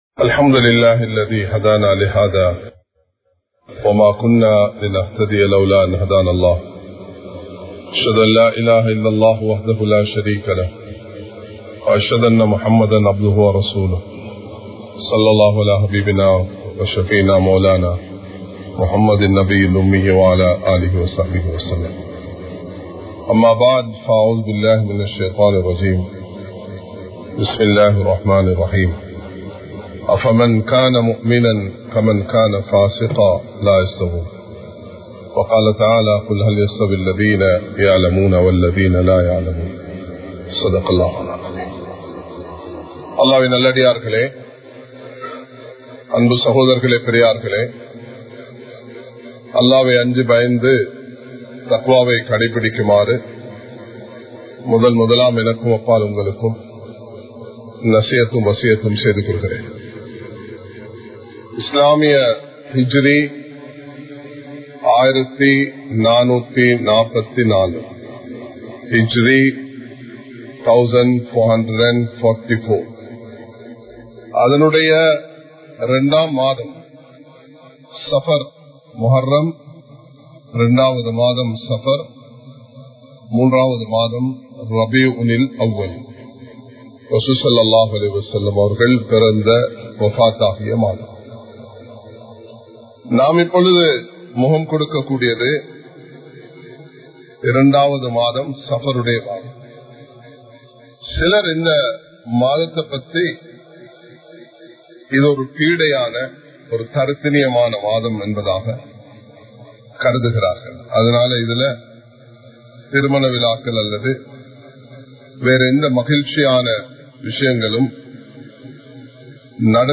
இஸ்லாம் கூறும் 04 அம்சங்கள் | Audio Bayans | All Ceylon Muslim Youth Community | Addalaichenai
Kollupitty Jumua Masjith